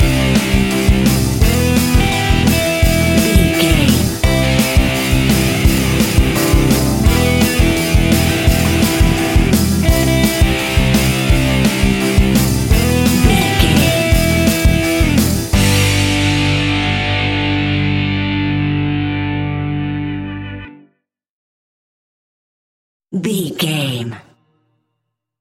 Epic / Action
Fast paced
Aeolian/Minor
pop rock
fun
energetic
uplifting
instrumentals
indie pop rock music
guitars
bass
drums
piano
organ